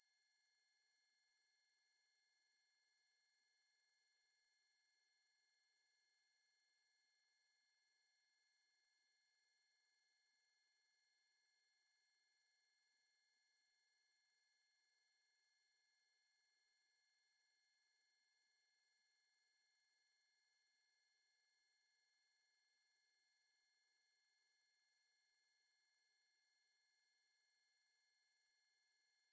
Звук незаметных высоких частот: едва уловимый тон, скрытый от большинства (если вы его не услышите, у вас могут быть проблемы со слухом)